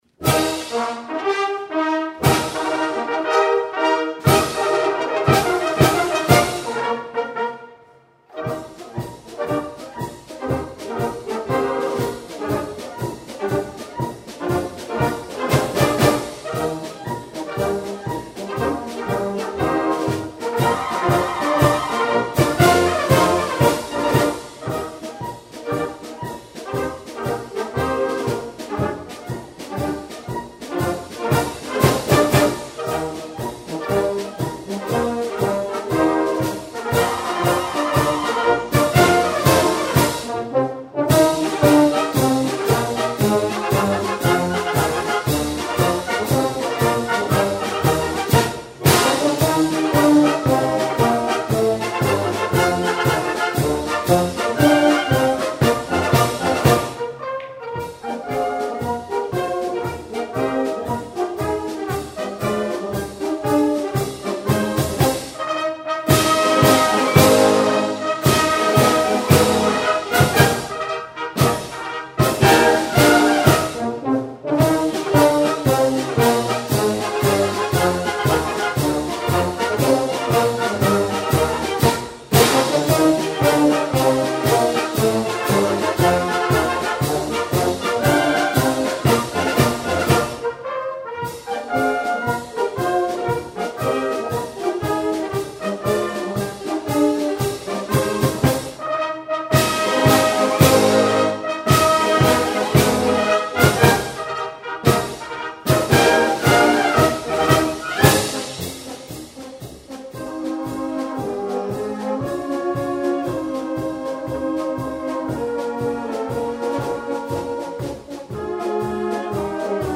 Rundfunk – Studioaufnahme in Salzburg (Die Kapelle hat gewonnen und Jugend heraus)